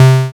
808s
Bass (22).wav